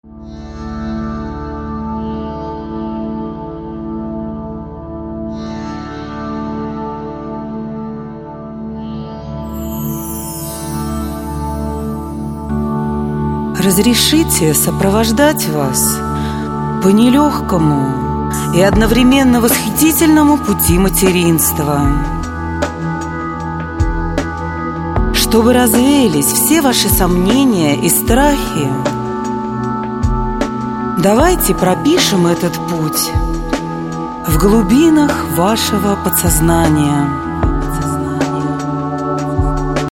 Они были созданы лично мной и воспроизведены на студии звукозаписи.
Все медитации я зачитываю СВОИМ ГОЛОСОМ!